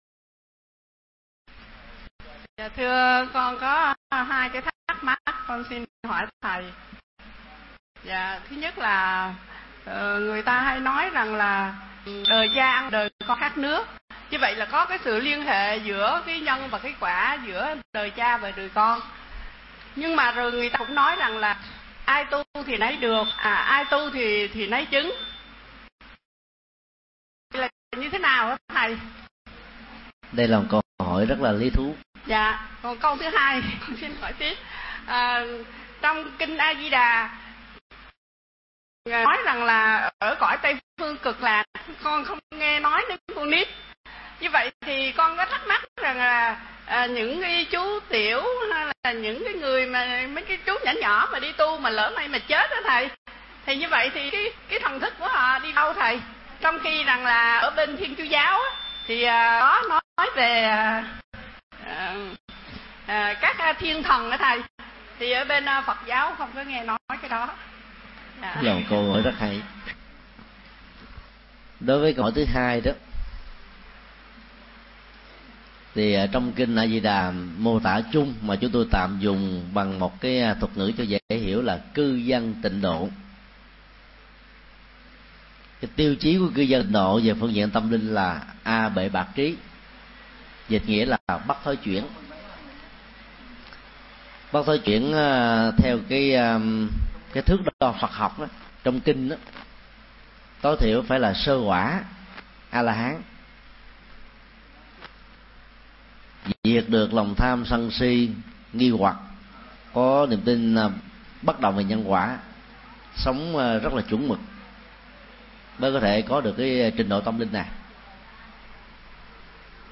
Vấn đáp Nguồn Gốc Con Người Và Bình Đẳng Giới - Thầy Thích Nhật Từ